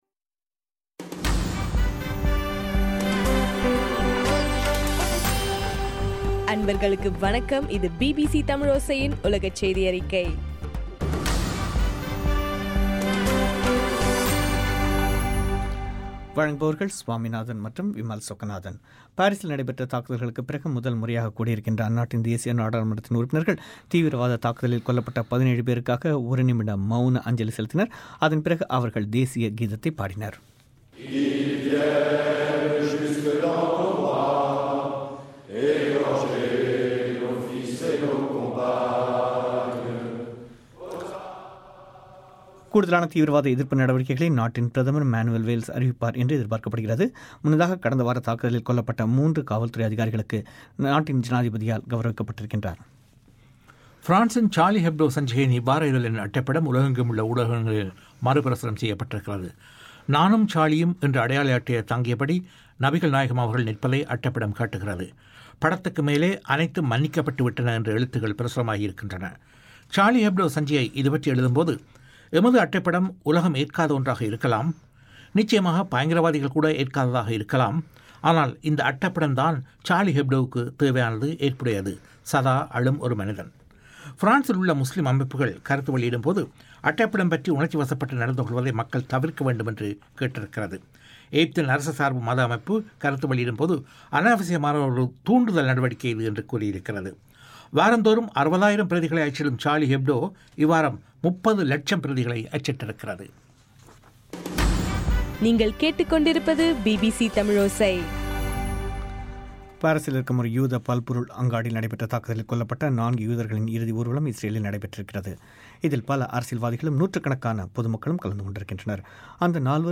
ஜனவரி 13 பிபிசியின் உலகச் செய்திகள்